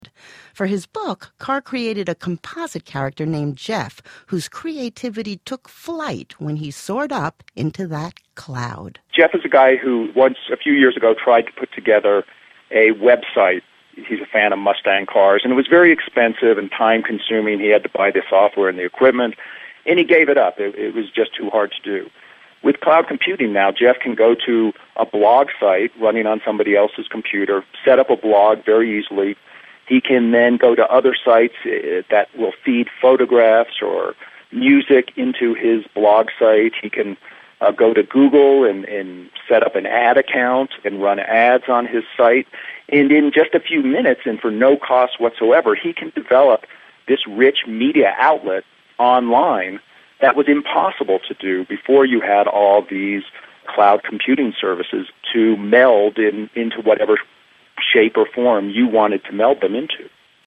Nicholas Carr elaborates upon this point in his book on the move to network computing, The big switch. Click here for an 8-minute interview of Carr or